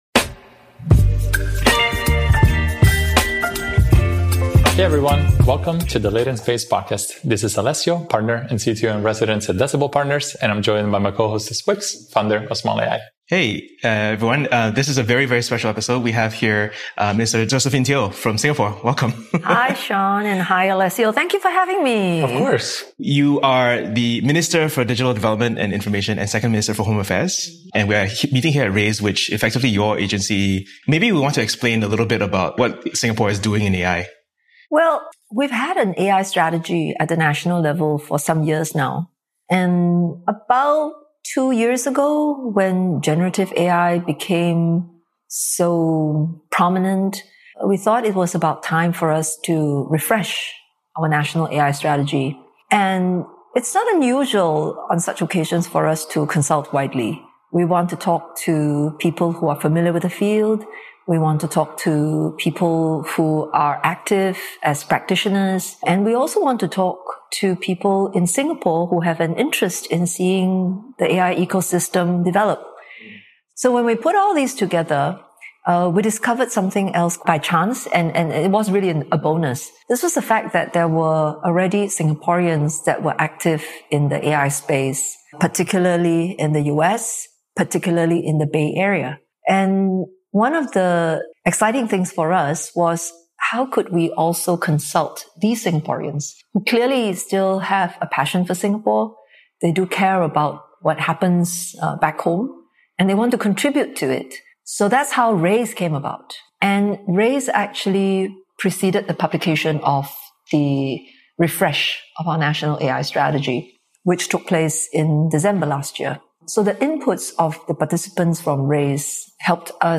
Building the AI Engineer Nation — with Josephine Teo, Minister of Digital Development and Information, Singapore